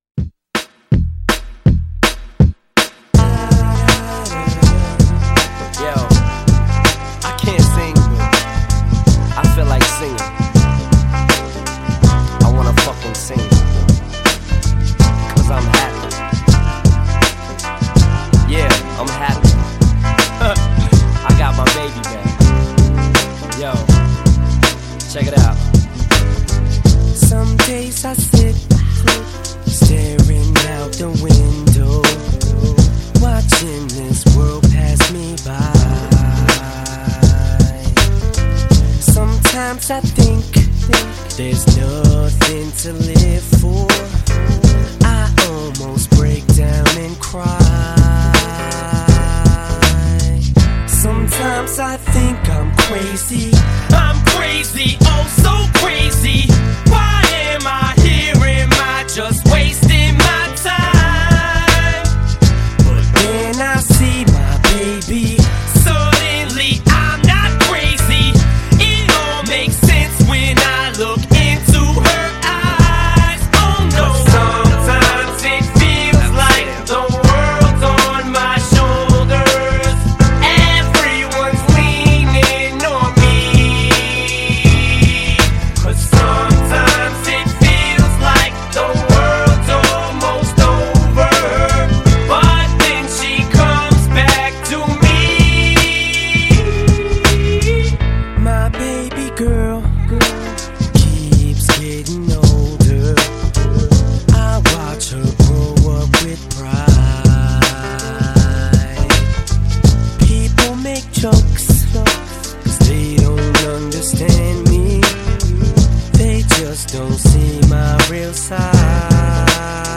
Жанр: Rap/Hip Hop